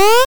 jump_sound.mp3